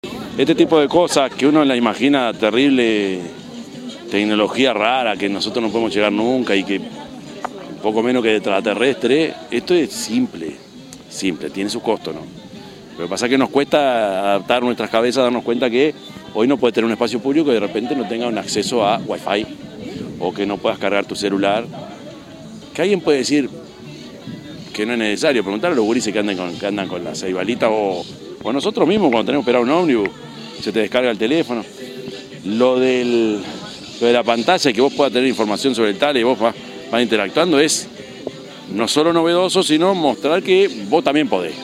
intendente_yamandu_orsi_0.mp3